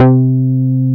R MOOG C4F.wav